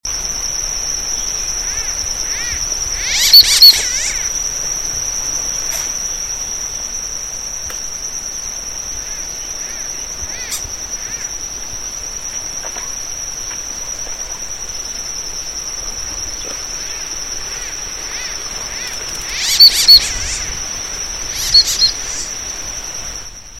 Anas bahamensis rubrirostris - Pato gargantilla